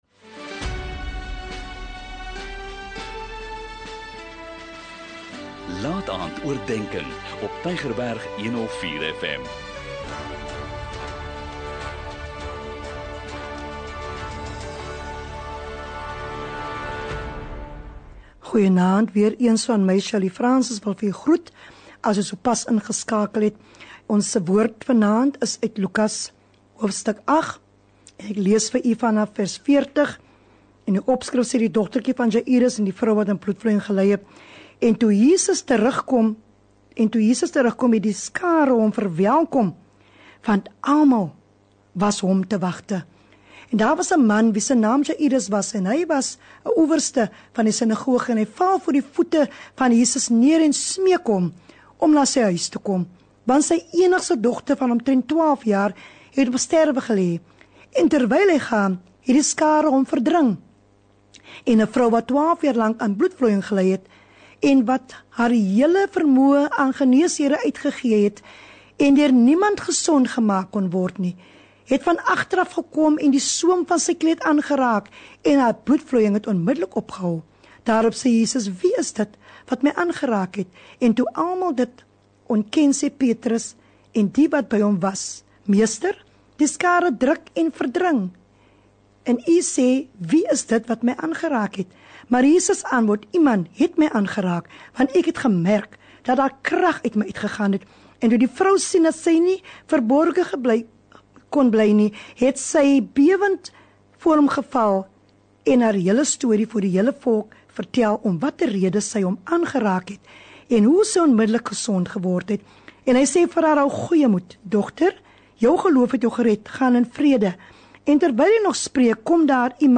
'n Kort bemoedigende boodskap, elke Sondagaand om 20:45, aangebied deur verskeie predikers.